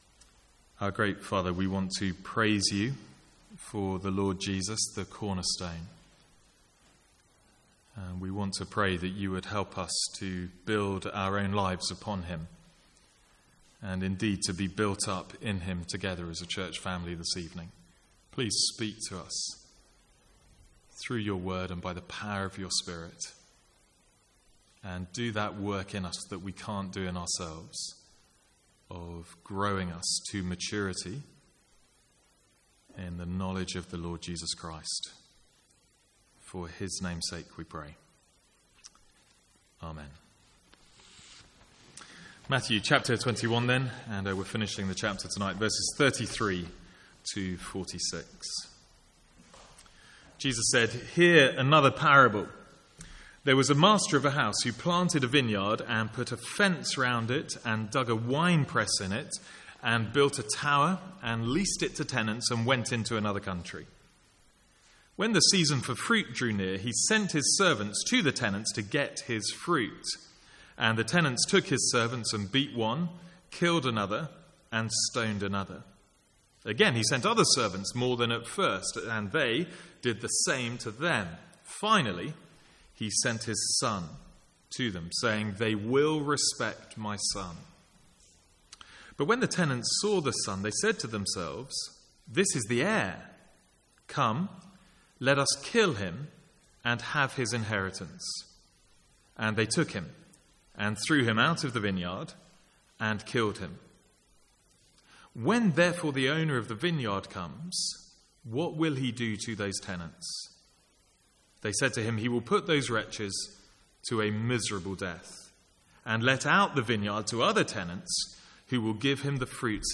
Sermons | St Andrews Free Church
From the Sunday evening series in Matthew.